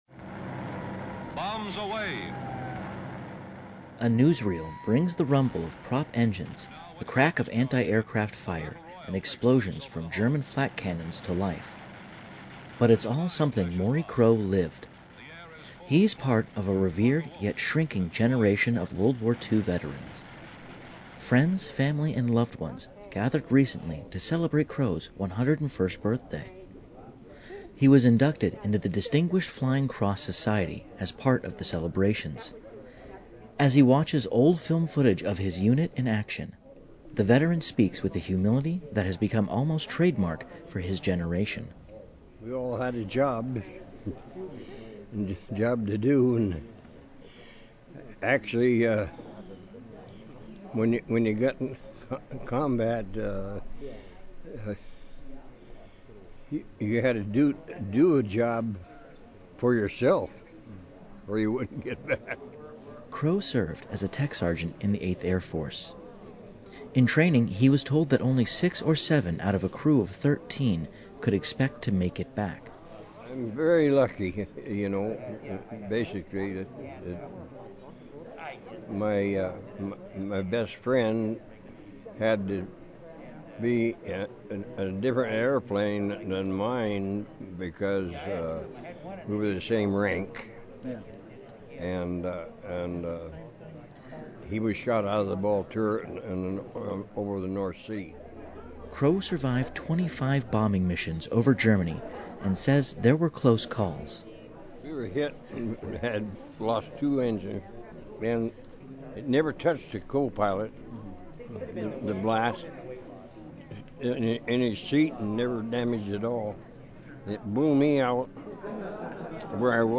A newsreel brings the rumble of prop engines, the crack of anti-aircraft fire and explosions from  German flak cannons to life.
news